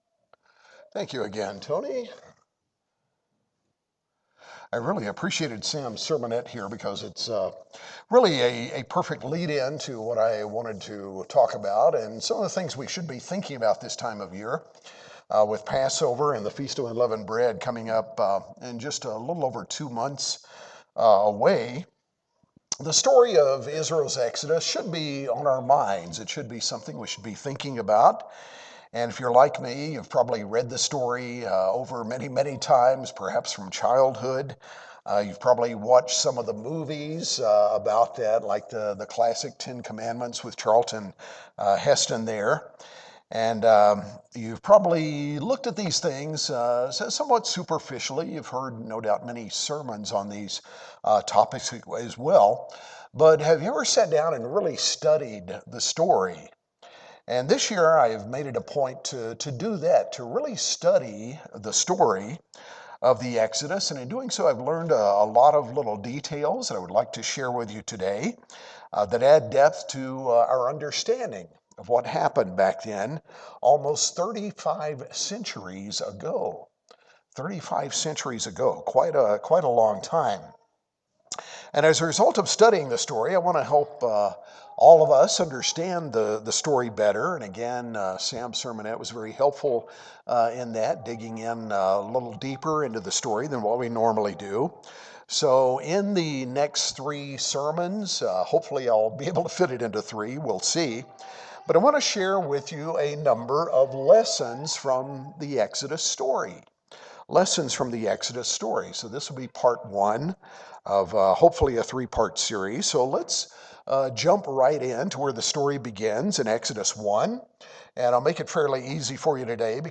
This astounding story has many lessons for Christians today. In part 1 of this series of sermons we’ll dig deeply into the background of the Exodus to see some of what God wants us to learn from this amazing story.